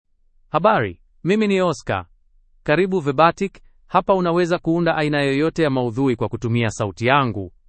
Oscar — Male Swahili AI voice
Oscar is a male AI voice for Swahili (Kenya).
Voice: OscarGender: MaleLanguage: Swahili (Kenya)ID: oscar-sw-ke
Voice sample
Listen to Oscar's male Swahili voice.
Oscar delivers clear pronunciation with authentic Kenya Swahili intonation, making your content sound professionally produced.